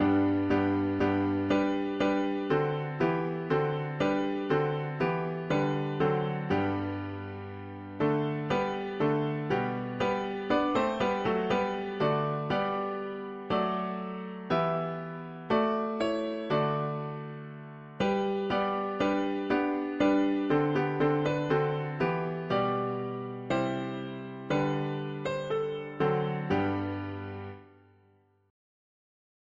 Bring forth the royal diadem, a… english christian 4part
Key: G major